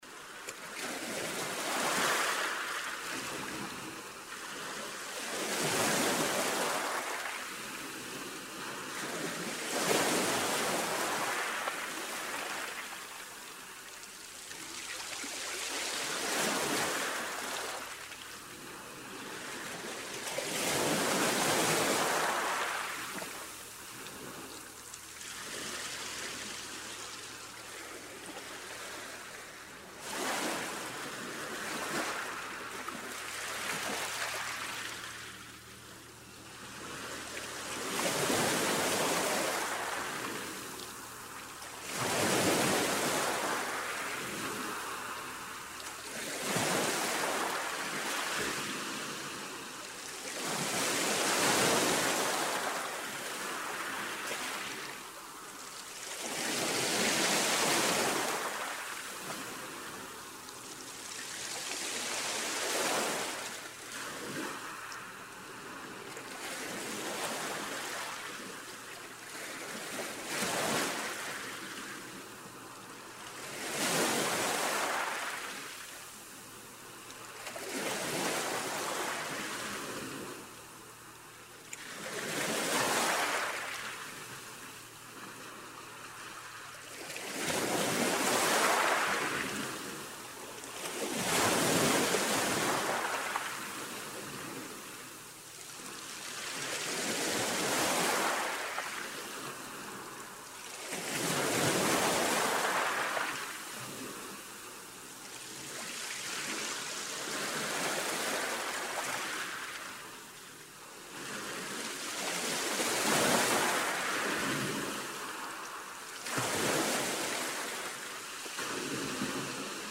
Звуки дайвинга
Здесь вы найдете записи шумов акваланга, пузырей воздуха, скрипа снаряжения и других уникальных звуков морских глубин.